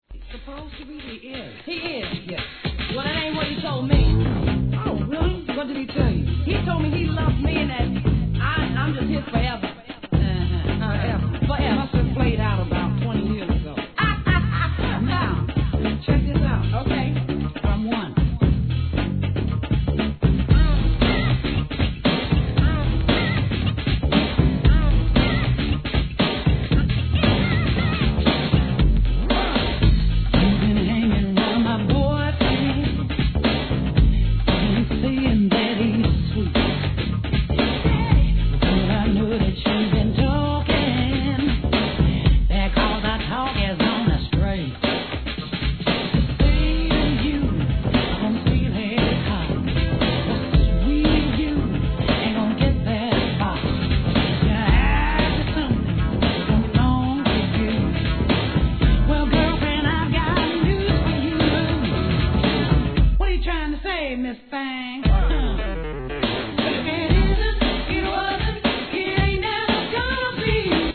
HIP HOP/R&B
HIP HOP REMIX